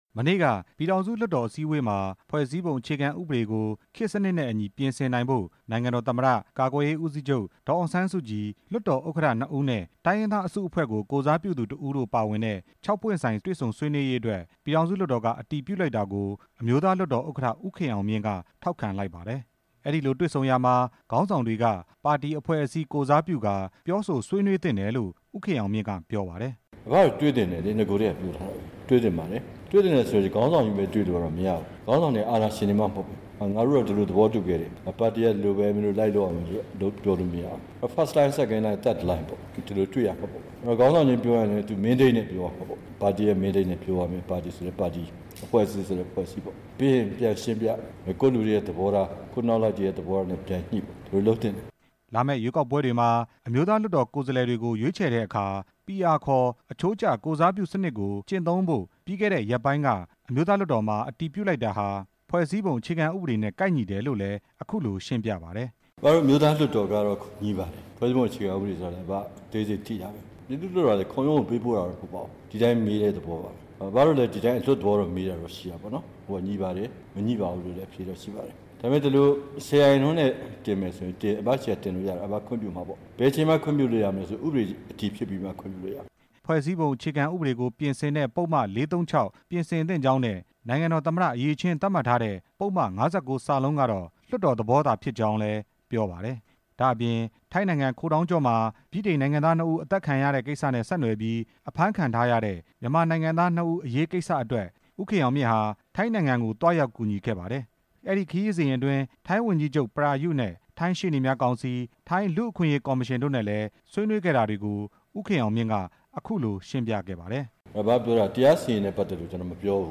သတင်းစာရှင်းလင်းပွဲမှာ အမျိုးသားလွှတ်တော်ဥက္ကဌ ဦးခင်အောင်မြင့်ရဲ့ ပြောကြားချက်တွေကို